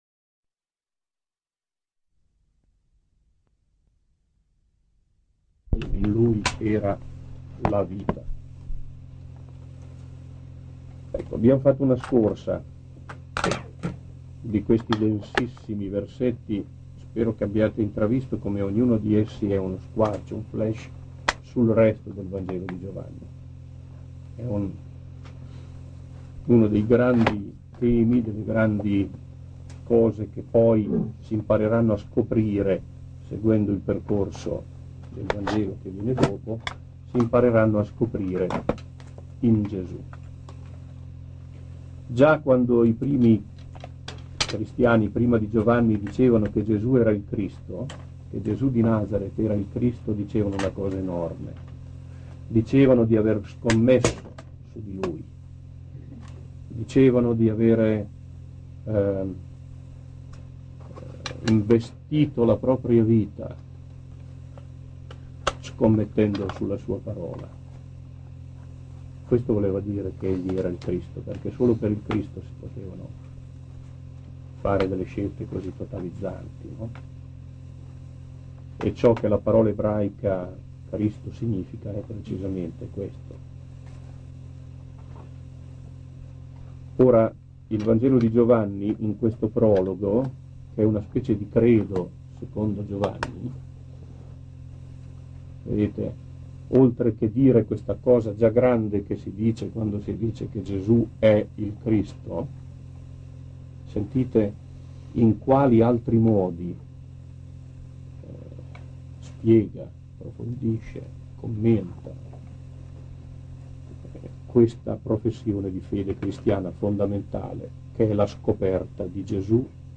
Significato di Cristo e Messia 1° Lezione parte 2